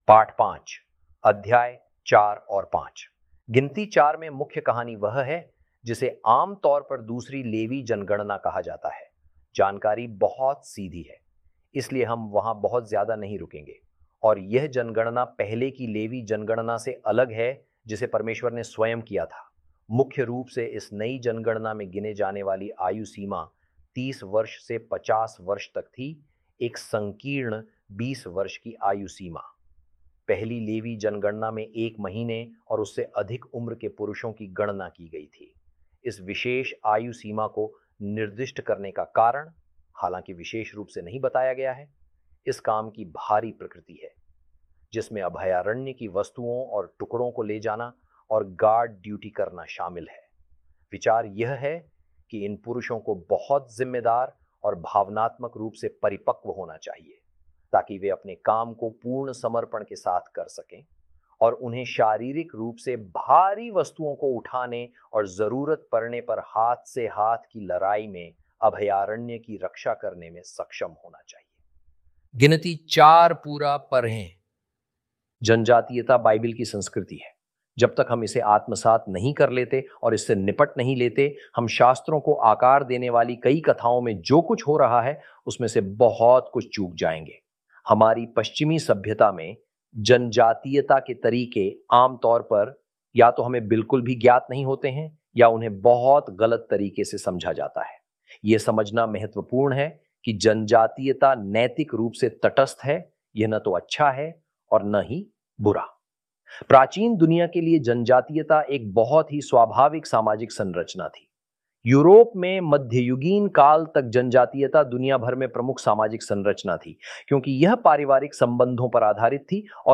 hi-audio-numbers-lesson-5-ch4-ch5.mp3